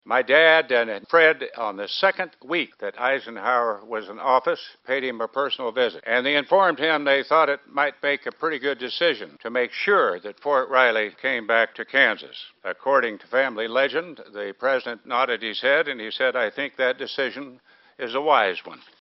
The event was standing room only while Senator Pat Roberts addressed the crowd, sharing a family story of his father who served in the Marines.